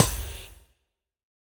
Minecraft Version Minecraft Version snapshot Latest Release | Latest Snapshot snapshot / assets / minecraft / sounds / block / trial_spawner / place3.ogg Compare With Compare With Latest Release | Latest Snapshot